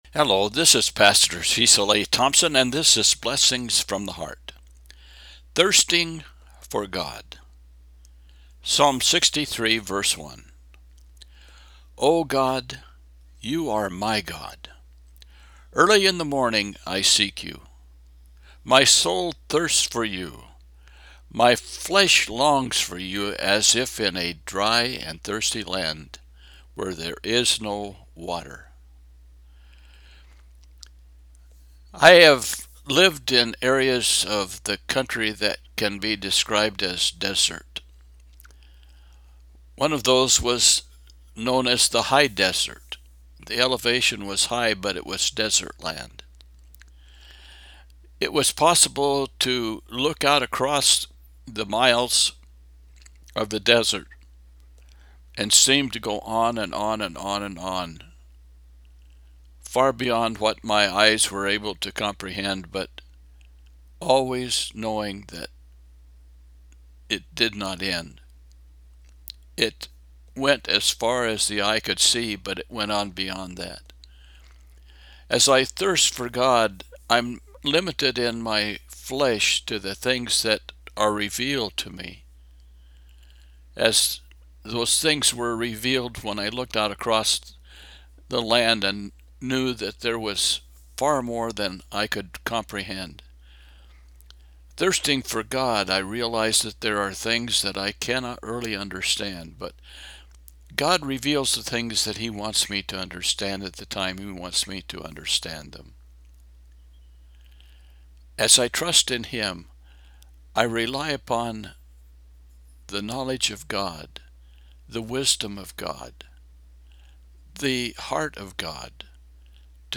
Psalm 63:1 –  Devotional